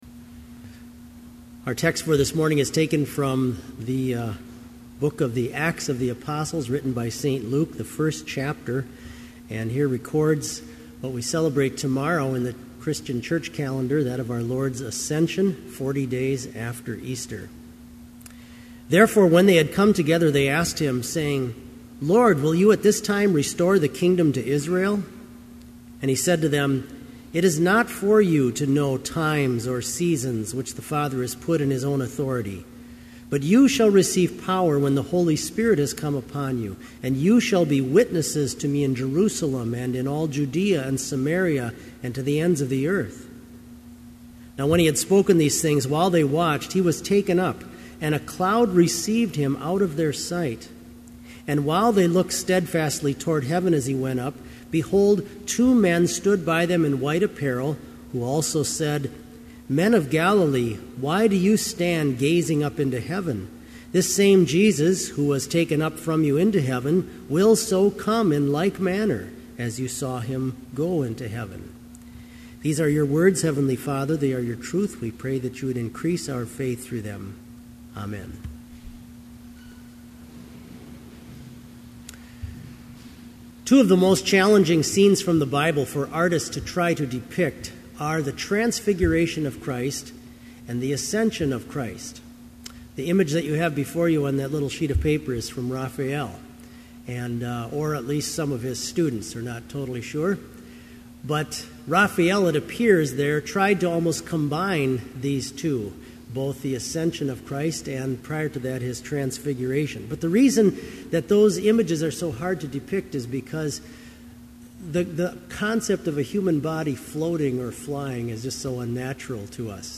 Complete service audio for Summer Chapel - June 1, 2011